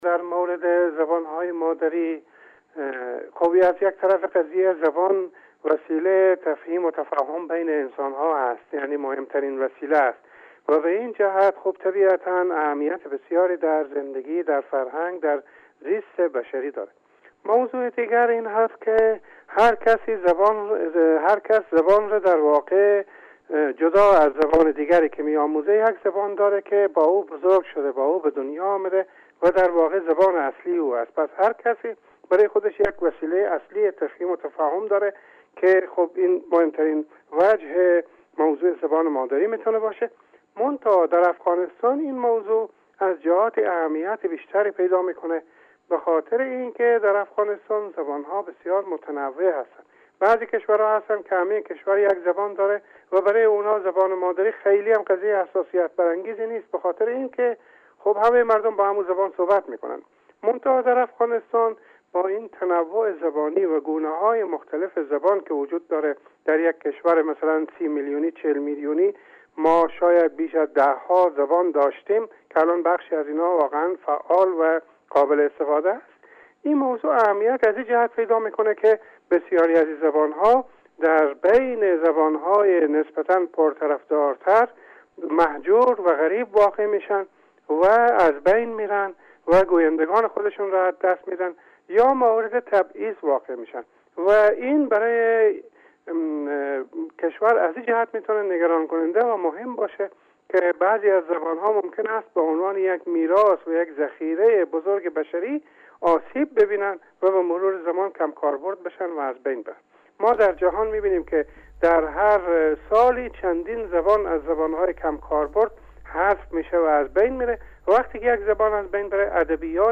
گفت وگوی اختصاصی